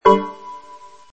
chord.mp3